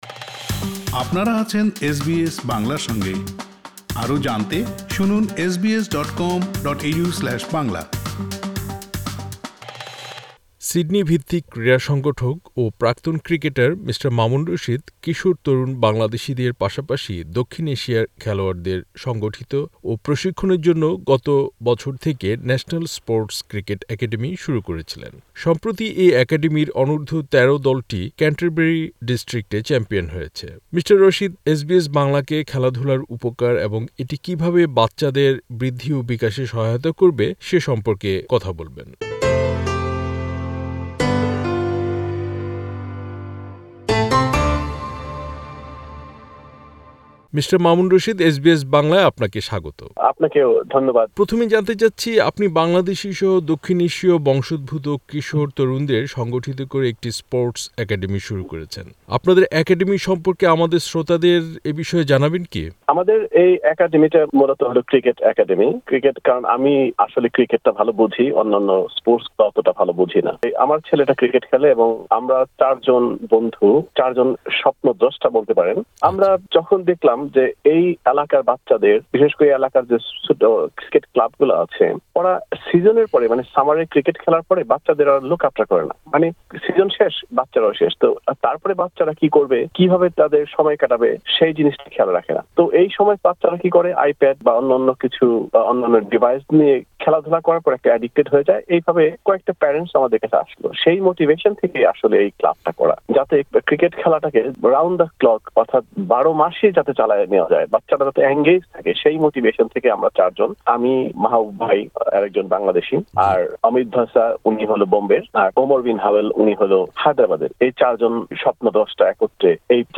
পুরো সাক্ষাতকারটি শুনতে উপরের অডিও প্লেয়ারে ক্লিক করুন।